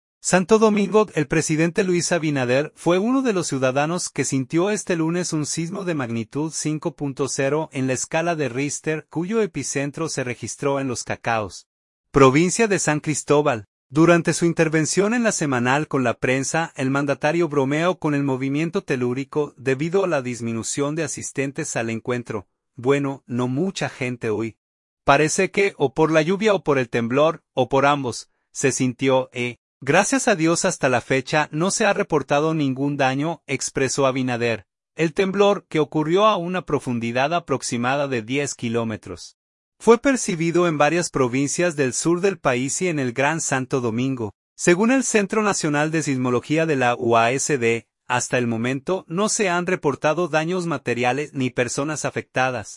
Durante su intervención en “La Semanal con la prensa”, el mandatario bromeo con el movimiento telúrico, debido a la disminución de asistentes al encuentro.